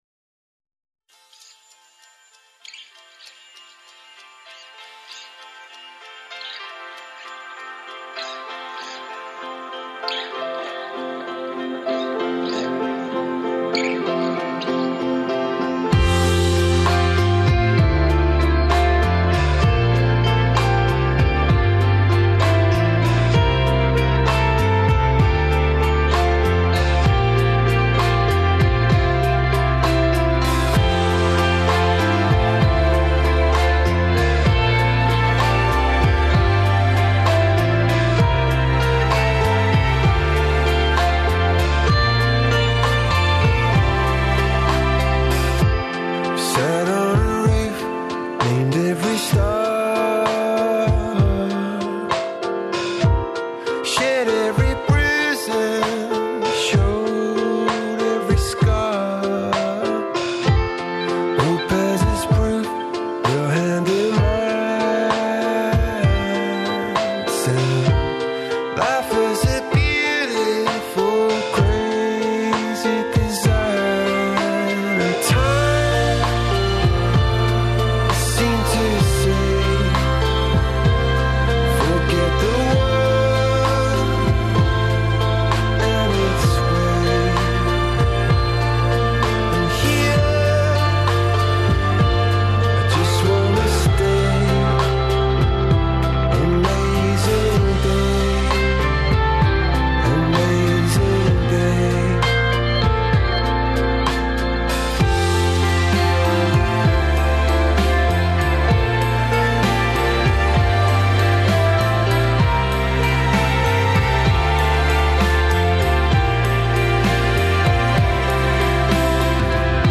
Били смо на Филолошком и Филозофском факултету у Београду и одатле доносимо све битне информације за будуће студенте ових установа, кроз рубрику ''Информатор''.